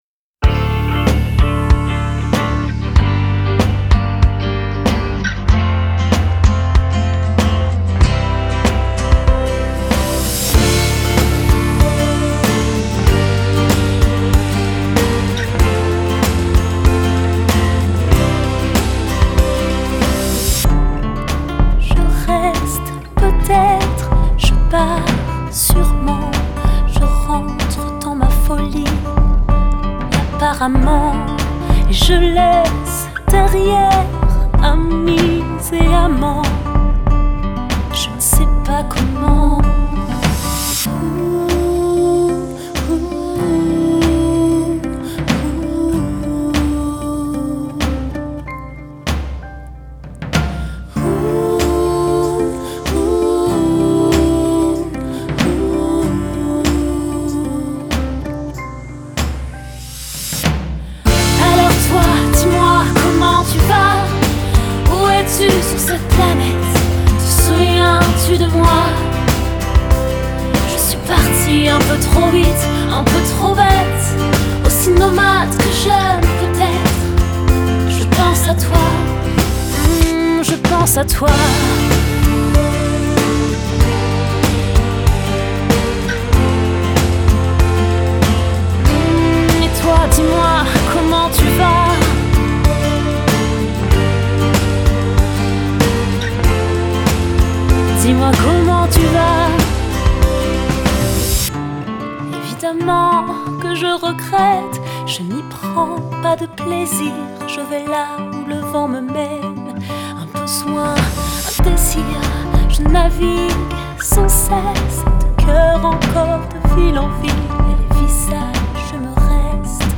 Genre: Pop, Dance, R&B, Soul, French